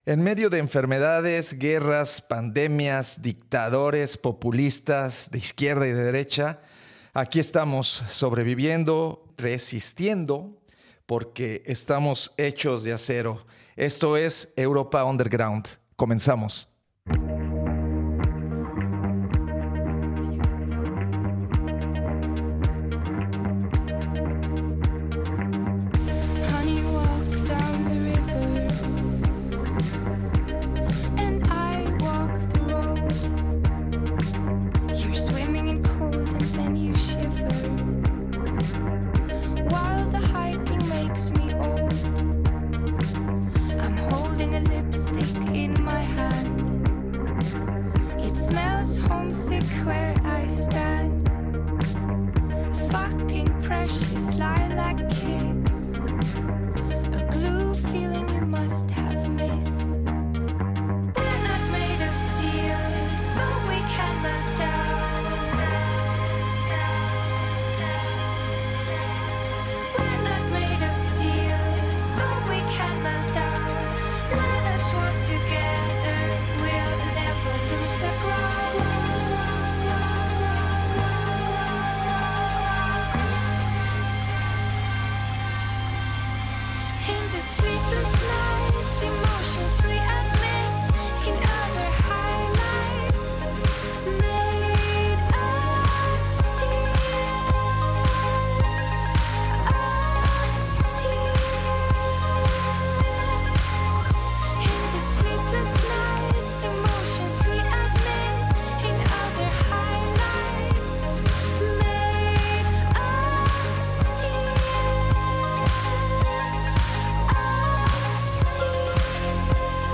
Europa Underground: una expedición por los sonidos alternativos del Viejo Continente